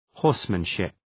{‘hɔ:rsmən,ʃıp}
horsemanship.mp3